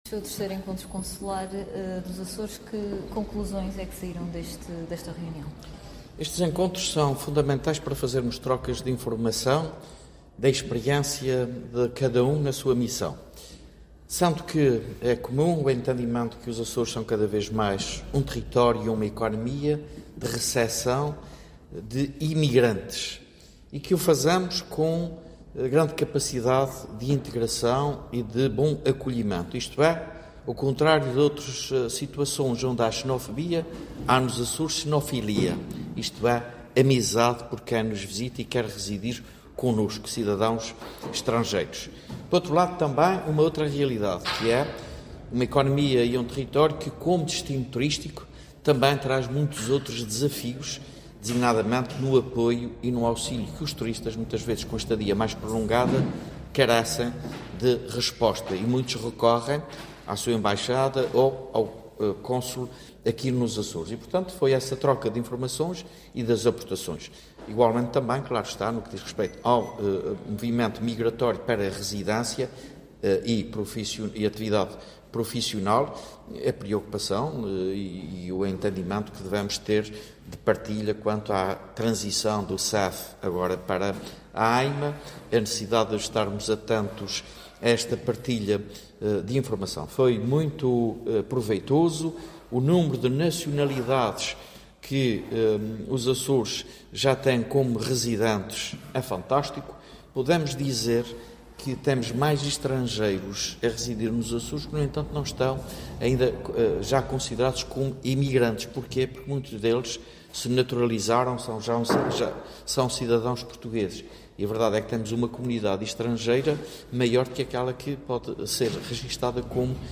O Presidente do Governo Regional dos Açores, José Manuel Bolieiro, presidiu hoje, em Ponta Delgada, ao III Encontro Consular dos Açores, com a participação dos membros do corpo consular da Região.